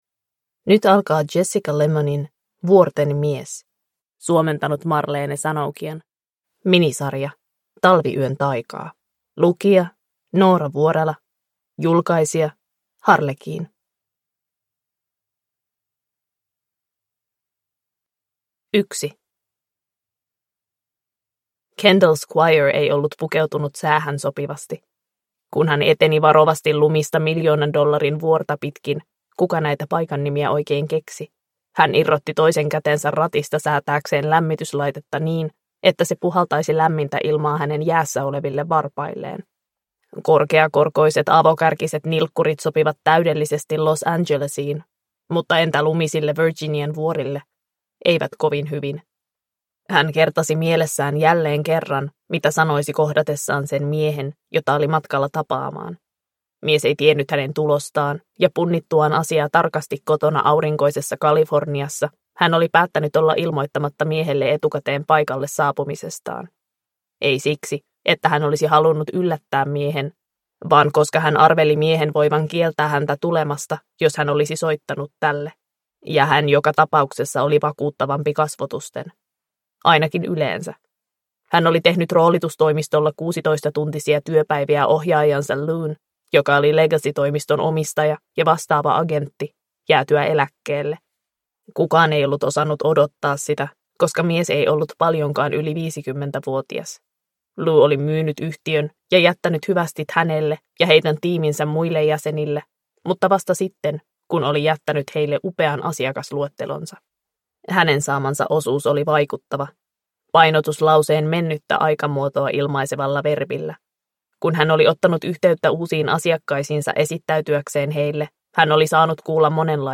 Vuorten mies – Ljudbok